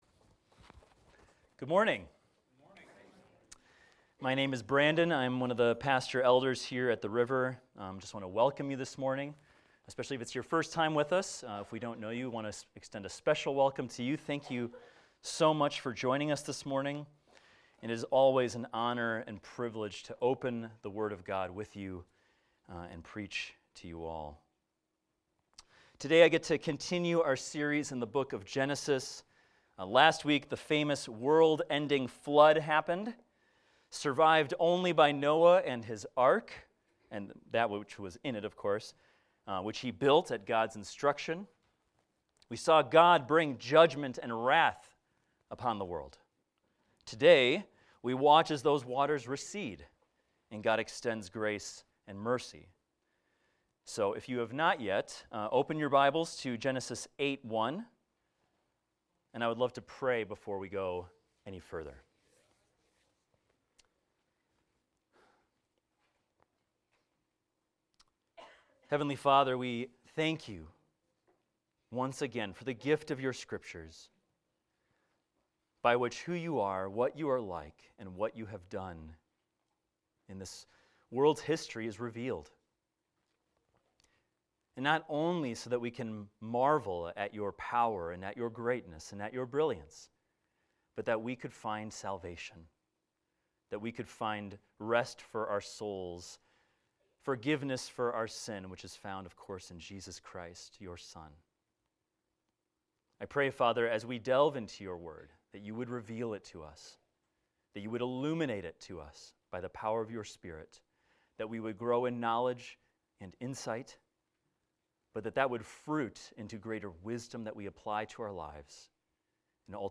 This is a recording of a sermon titled, "God Remembered Noah."